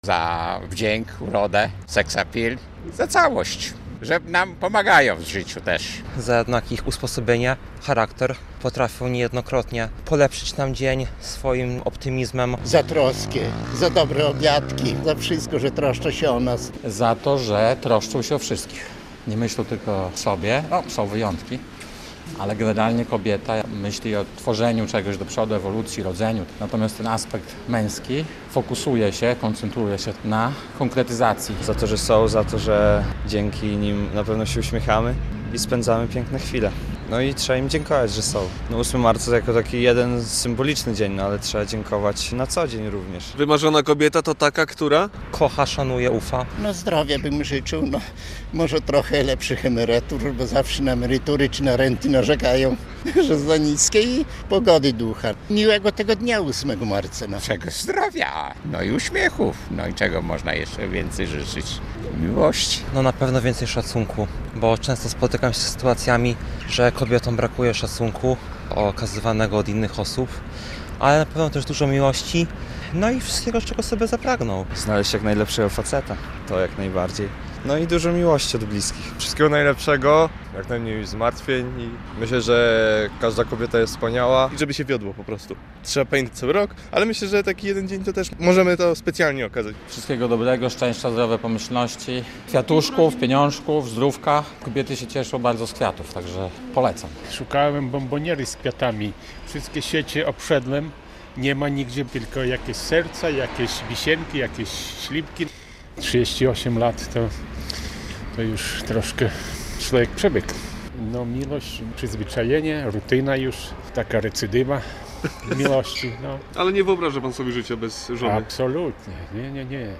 Na ulicach Białegostoku i Łomży można było spotkać naszych reporterów, którzy przygotowali wyjątkowy konkurs dla pań i nie tylko.